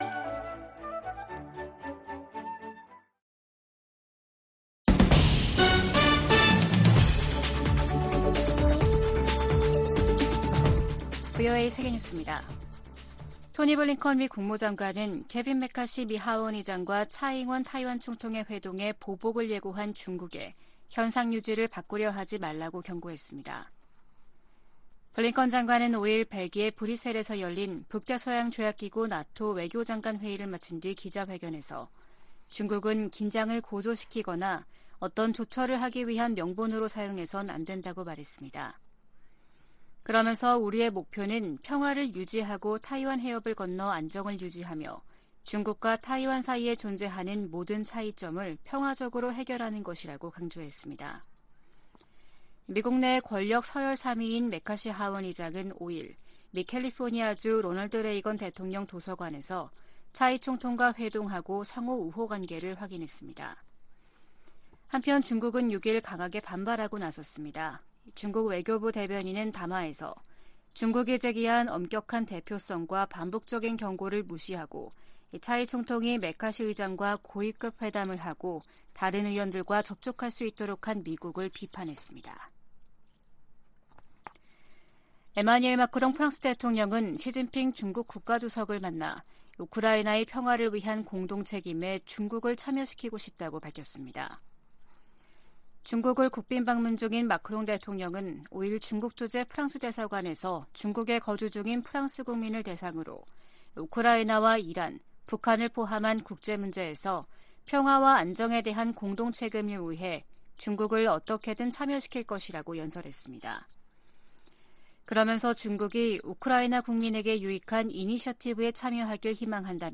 VOA 한국어 아침 뉴스 프로그램 '워싱턴 뉴스 광장', 2023년 4월 7일 방송입니다. 윤석열 한국 대통령은 최근 북한 간첩단 적발과 관련해 국민들이 현혹되지 않도록 대응 심리전이 필요하다고 강조했습니다. 북한이 아프리카 등지에 계속 군사 장비를 수출하고 있다고 유엔 안보리 대북제재위원회 전문가패널이 밝혔습니다. 한국을 방문한 미국 의원들은, 윤석열 대통령을 면담하고, 양국 무역과 투자 강화 방안을 논의했습니다.